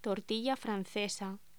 Locución: Tortilla francesa
voz